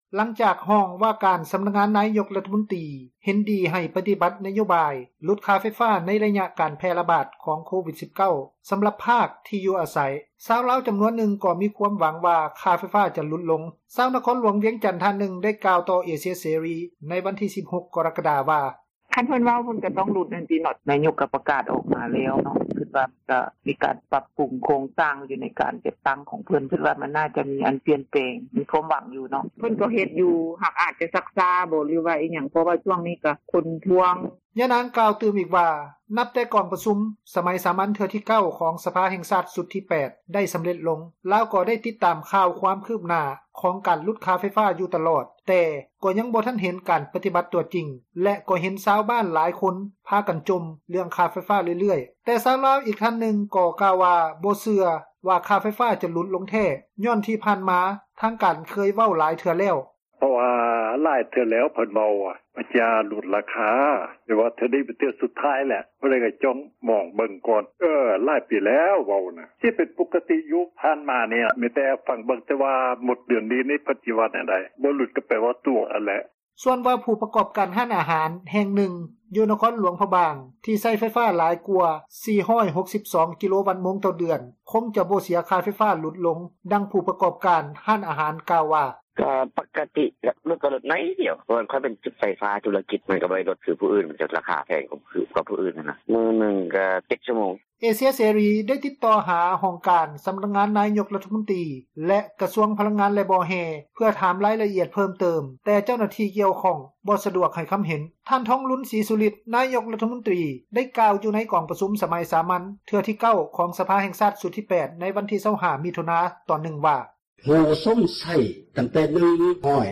ຣັຖບານຫຼຸດຄ່າໄຟຟ້າ ຮອດທ້າຍປີ – ຂ່າວລາວ ວິທຍຸເອເຊັຽເສຣີ ພາສາລາວ